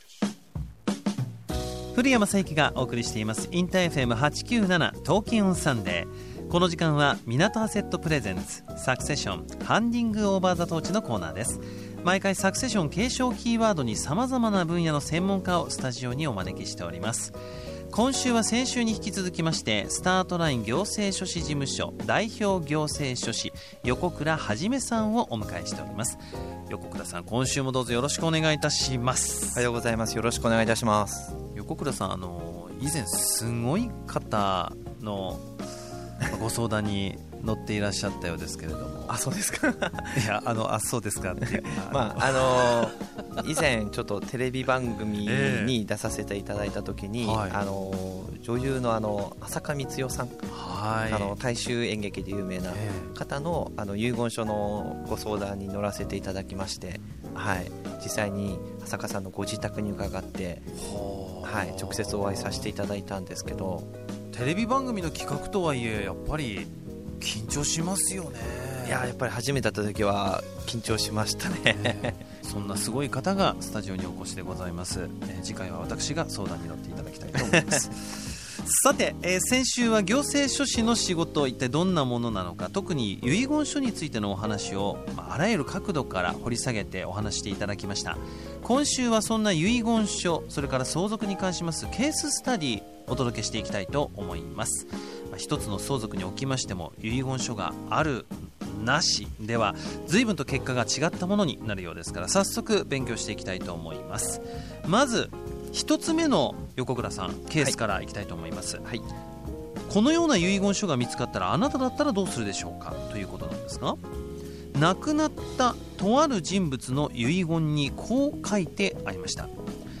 カテゴリ: ラジオ